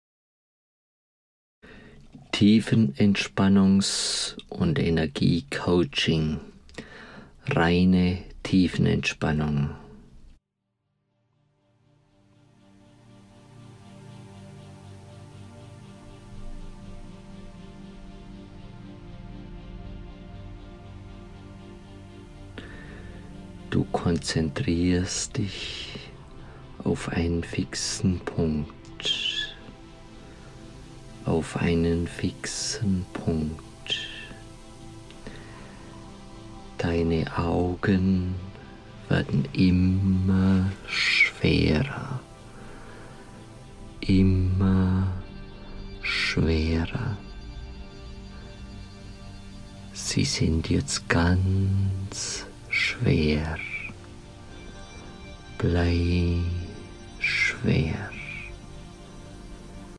Höre auf die Stimme des Audio-Hometrainers & atme ruhig. Konzentriere dich auf die angesagten Körperpunkte & Körperbereiche.
Lassen Sie sich durch die Worte Ihres LOT-MEDIA-COACHS begleiten& leiten zu neuer Achtsamkeit auf der Reise durch Ihren Körper.